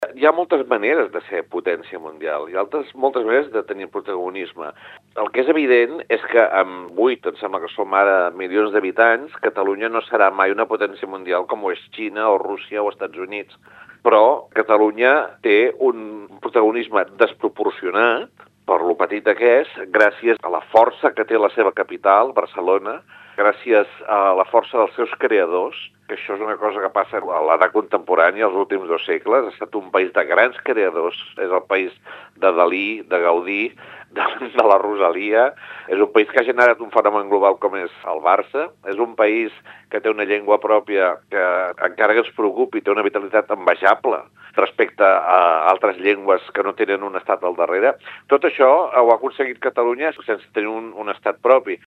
Toni Soler, monologuista de  ‘Per què Catalunya no és una potència mundial?’
Toni-Soler-01.-Monoleg-conferencia.mp3